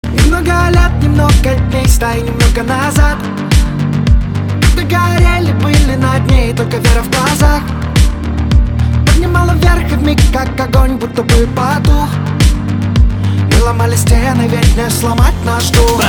• Качество: 320, Stereo
позитивные
мужской вокал
Хип-хоп
мотивирующие
hip-house